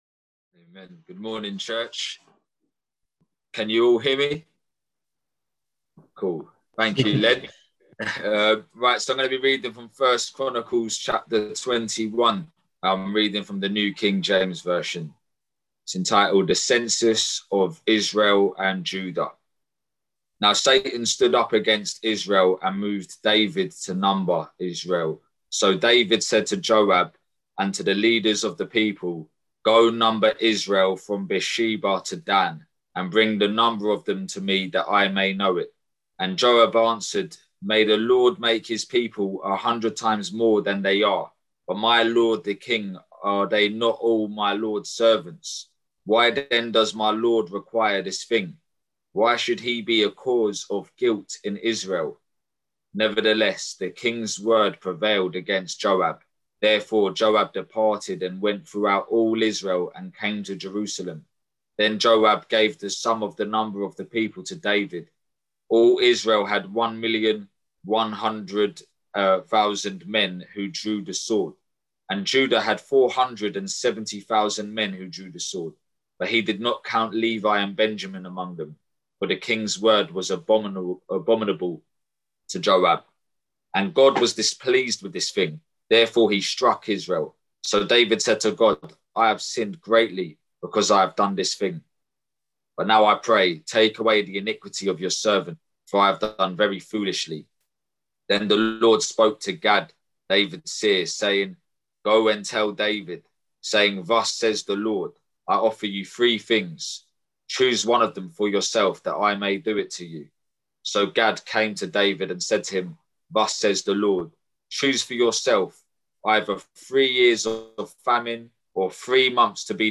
Below is the recording of the sermon for this week.